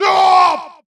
peachCutVoice.wav